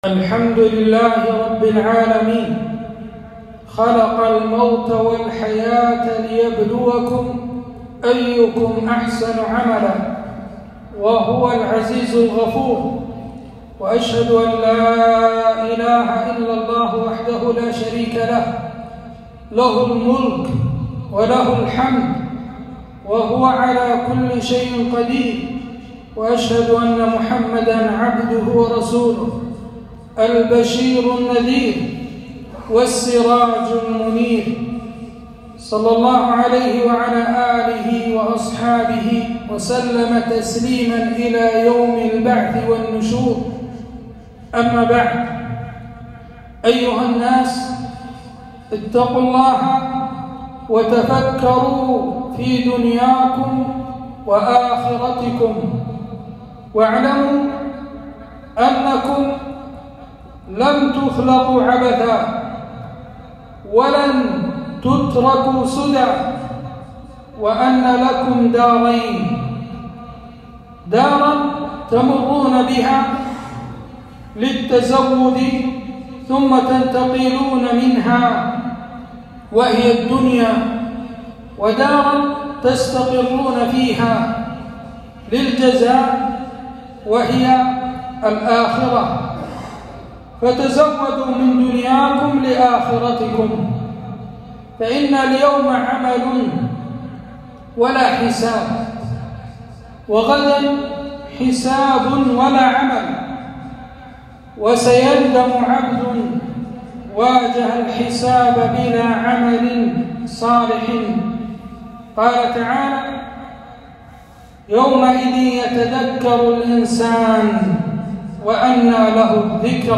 خطبة - اللهم لا تجعل الدنيا أكبر همنا